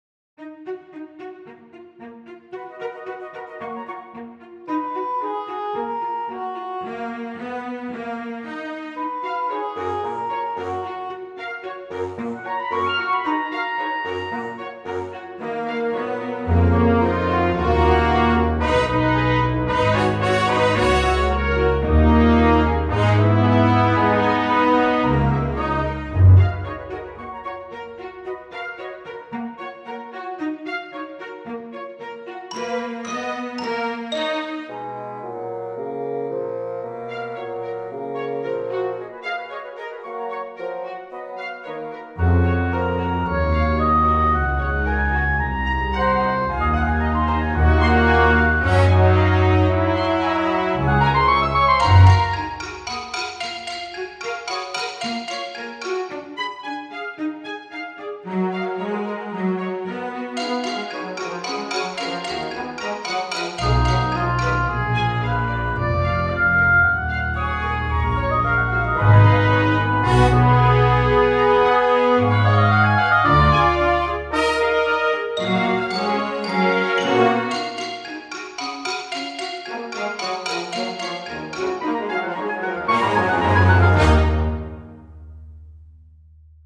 I decided for more conventional harmony and stuck to 2-3 motifs that stood out (for me).
The piece is in Eb Minor and jumps to B Major, Db Major and Gb Major.
The key changes work reasonably well, but seem somewhat unmotivated.
What does not work for me: The unmotivated hammering on the marimba.